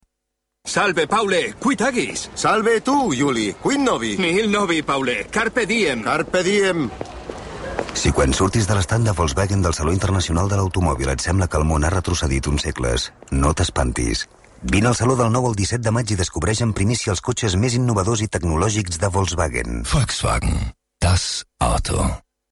Un anunci a la ràdio
Ara fa uns dies, amb motiu de la inauguració del Saló Internacional de l’Automòbil a Barcelona, vaig sentir un anunci de la casa Volkswagen que començava amb un diàleg entre dues persones en llatí: